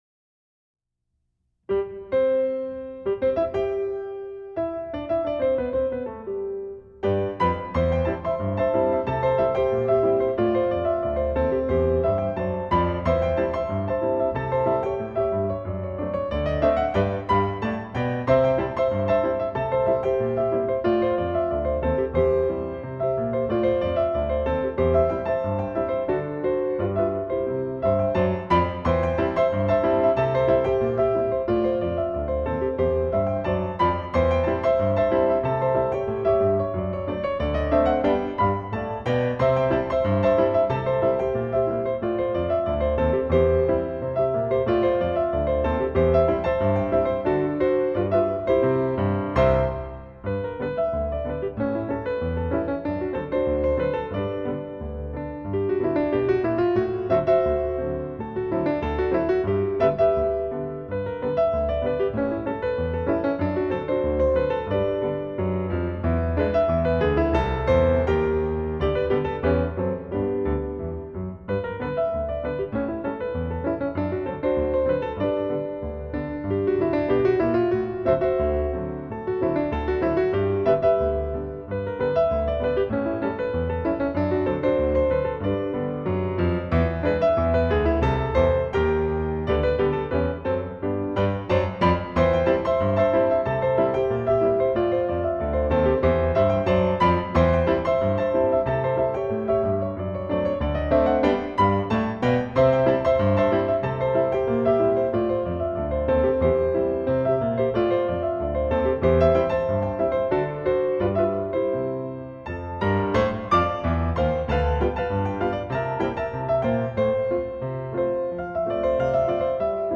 Piano: Hamburg Steinway Model D